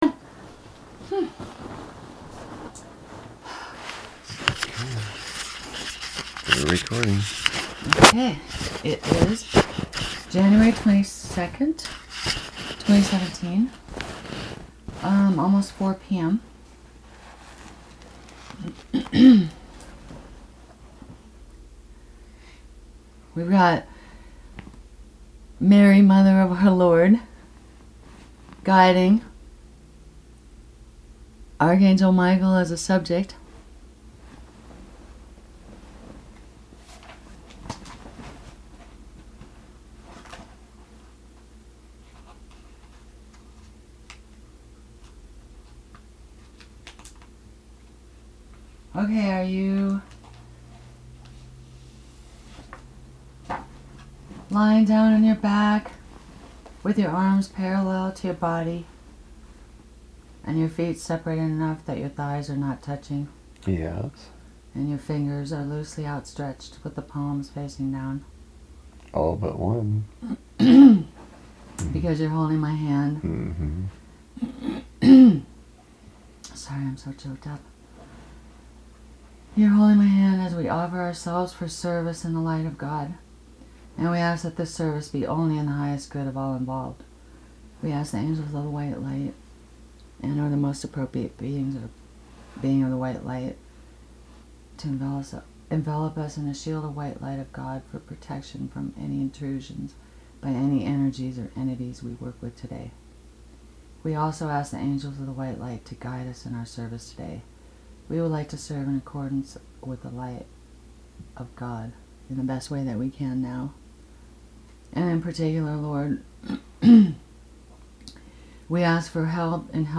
[Fast forward to 11 minutes in the recording if you want or need to skip the induction into altered state of consciousness.]